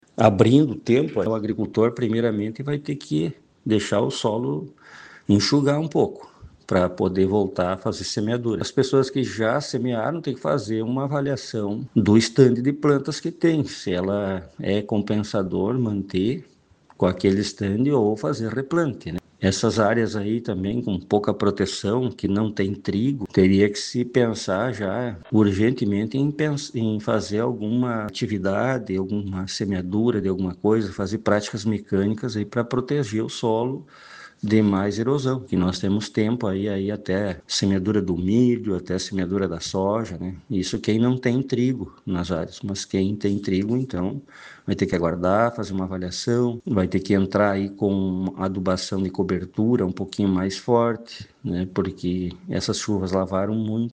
Abaixo, sonora